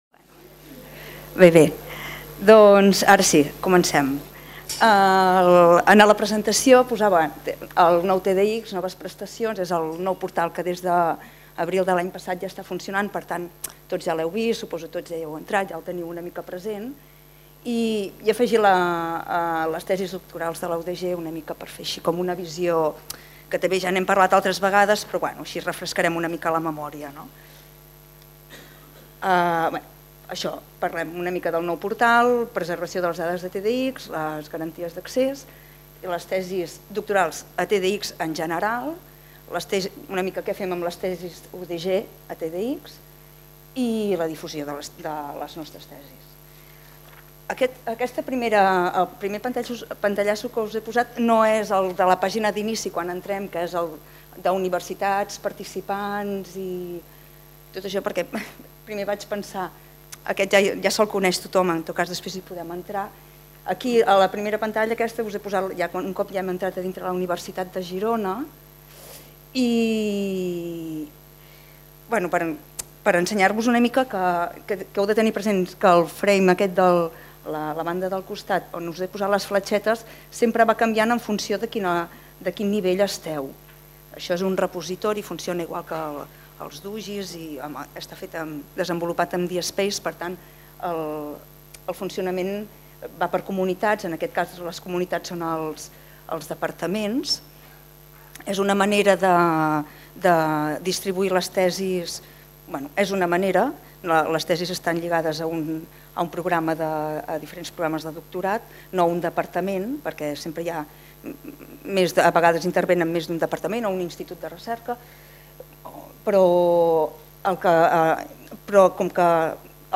Conferència emmarcada dins el Vè Workshop BiblioUdG on s'expliquen les noves prestacions del nou portal del TDX (Tesis Digitals en Xarxa), la preservació de les dades i la garantia d'accés i visibilitat de TDX i les tesis UdG a TDX i la seva difusió